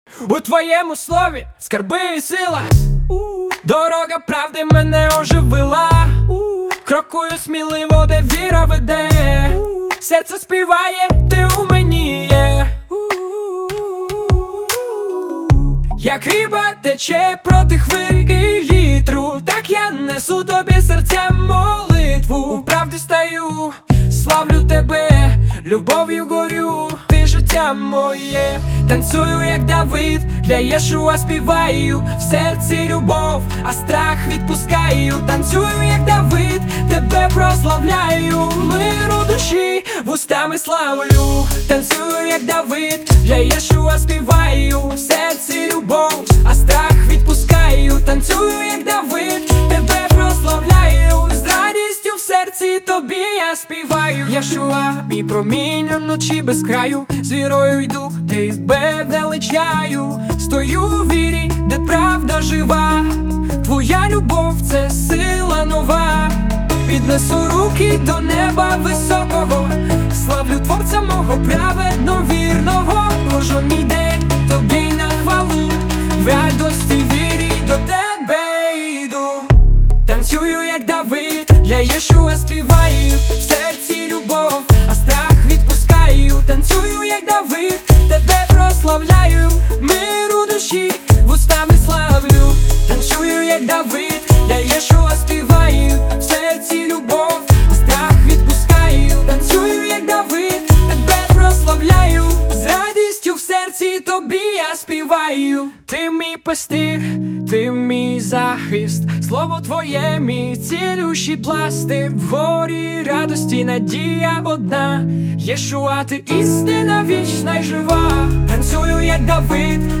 152 просмотра 259 прослушиваний 5 скачиваний BPM: 150